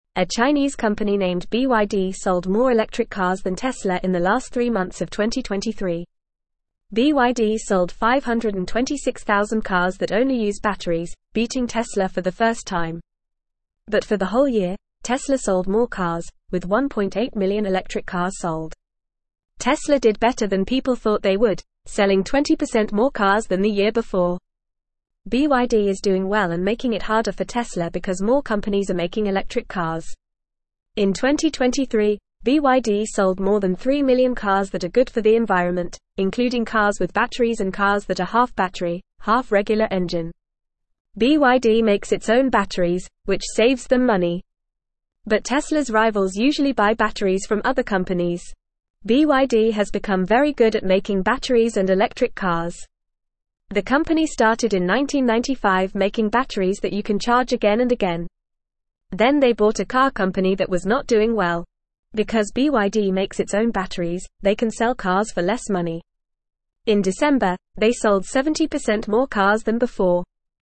Fast
English-Newsroom-Lower-Intermediate-FAST-Reading-BYD-sells-more-electric-cars-than-Tesla.mp3